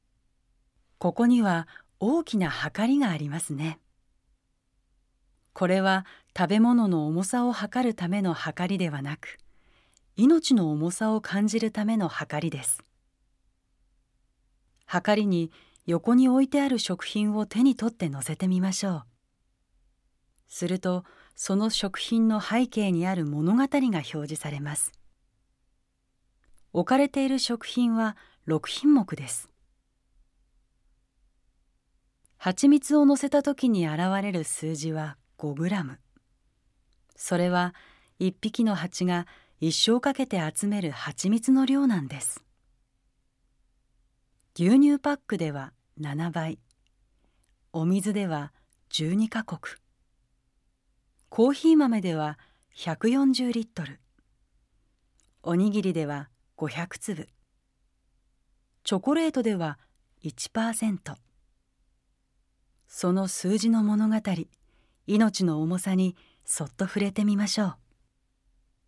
音声ガイドナレーター：宇賀なつみ